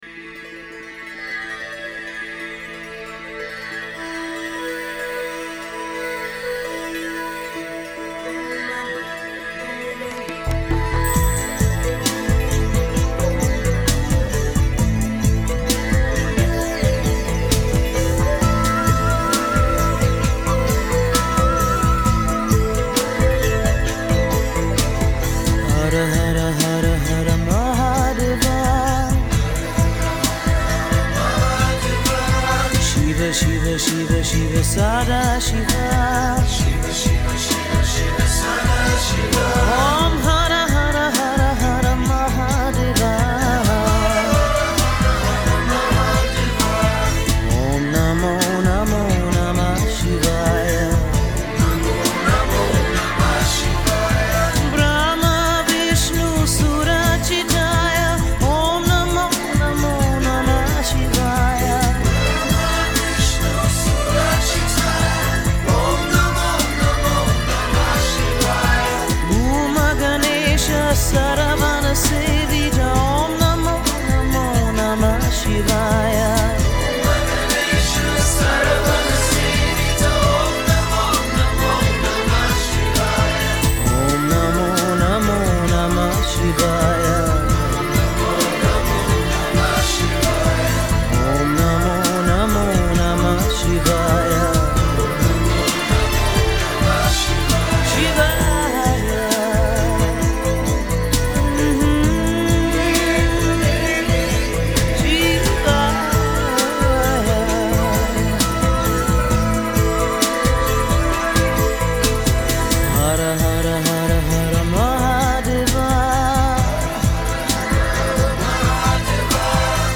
Индийские бажданы в шедевральной аранжировке.
Стиль: Ethnic New Age UK / USA